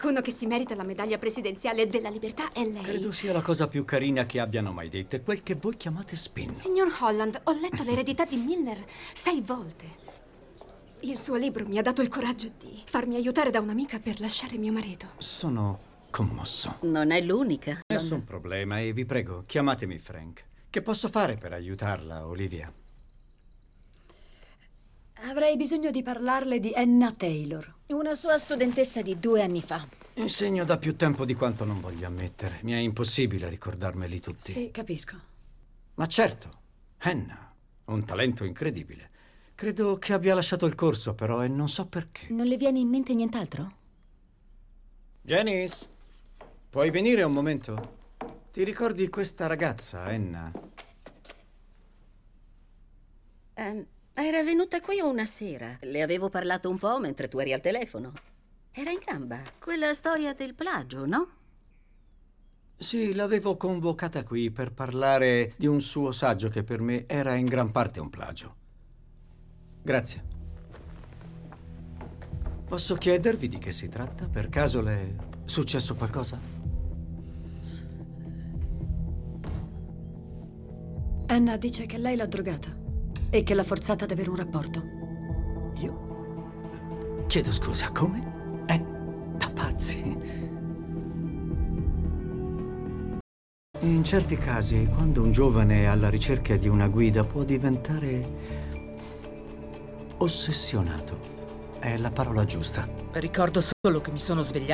nel telefilm "Scandal", in cui doppia William Russ.